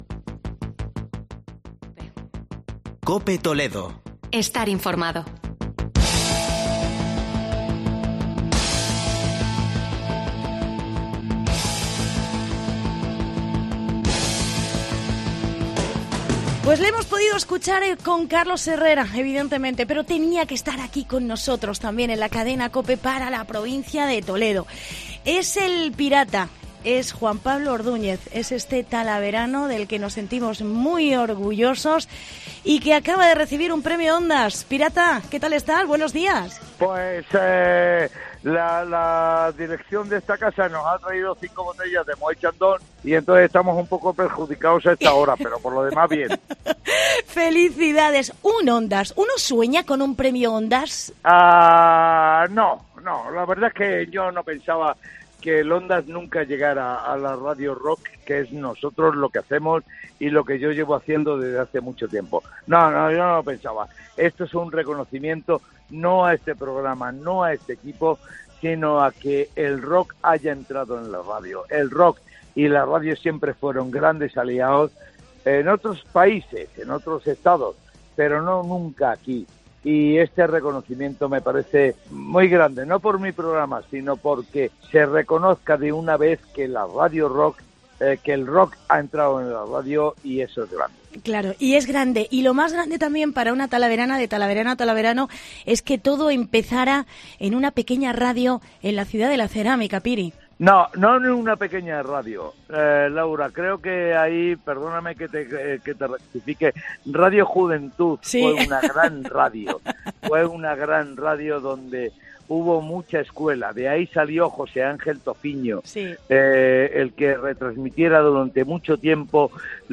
Entrevista con El Pirata tras su Premio Ondas